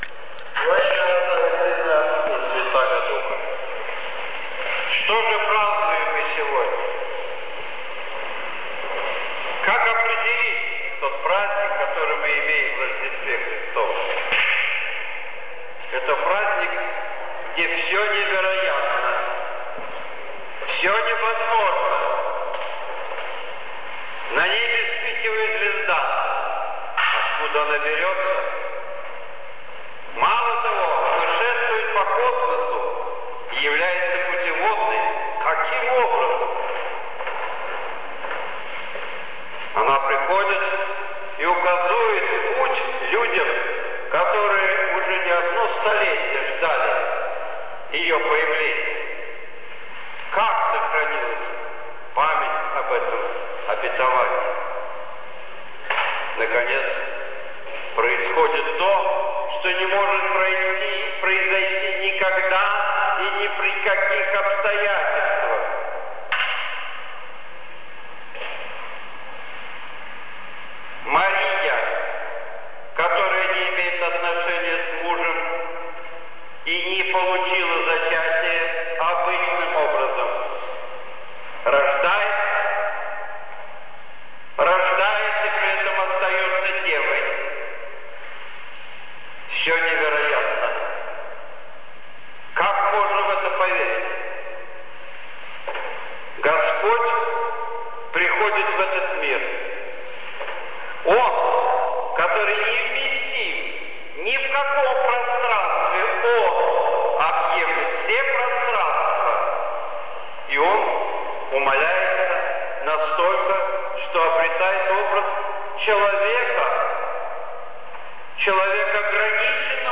6 января 2014 г.Навечерие Рождества Христова